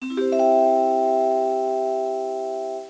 chime1.mp3